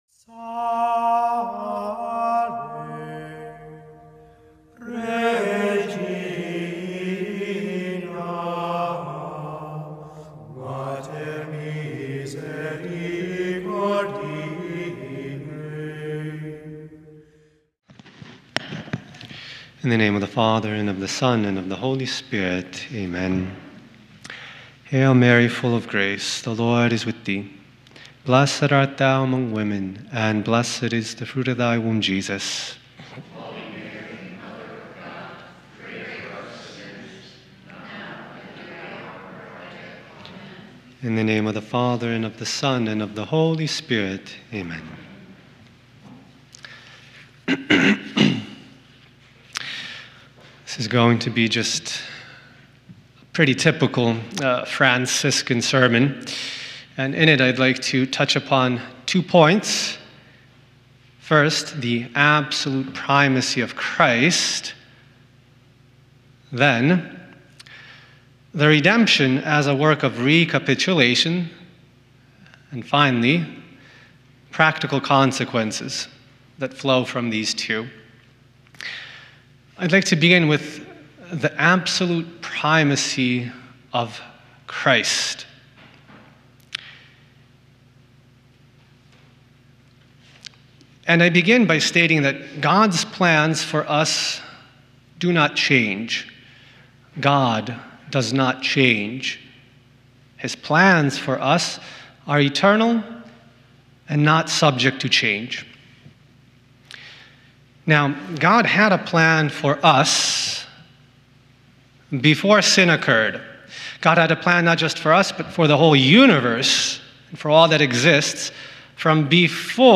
Homily
Mass: Sunday 1st Week of Lent - Sunday Readings: